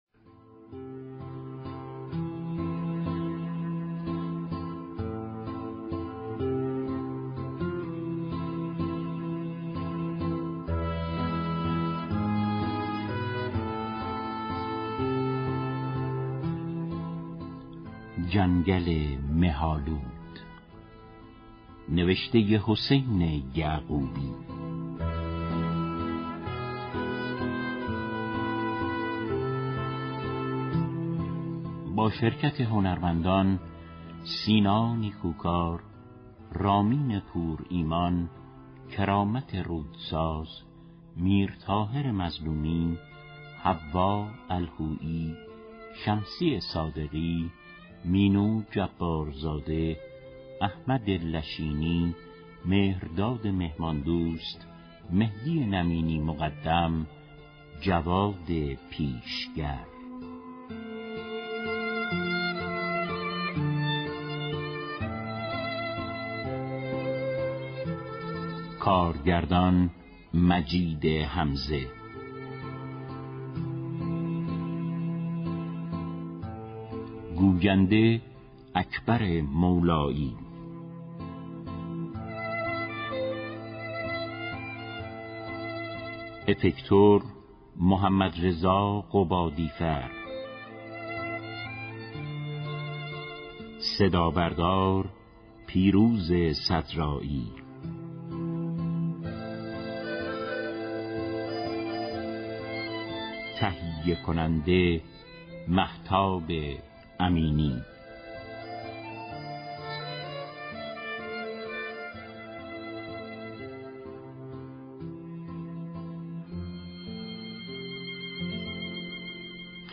پخش سریال پلیسی جدید از رادیو نمایش
از ششم دی ماه ، سریال پلیسی جدید